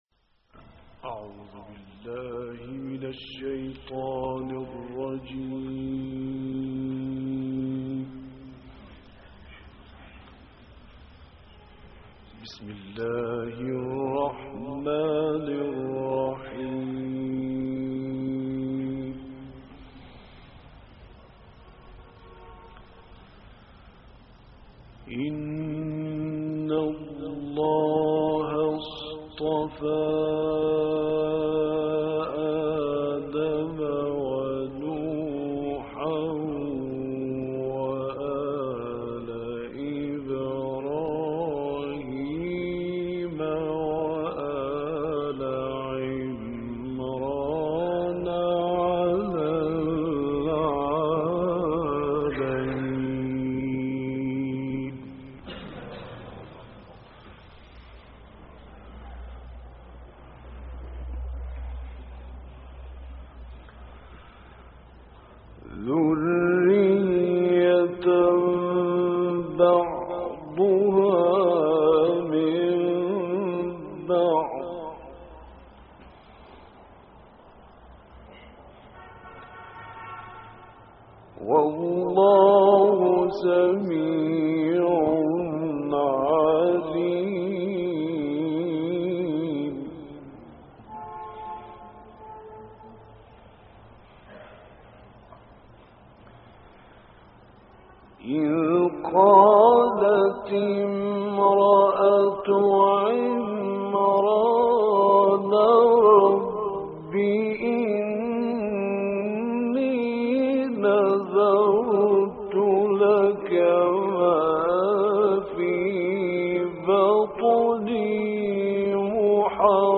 دانلود قرائت سوره آل عمران آیات 33 تا 49 - استاد راغب مصطفی غلوش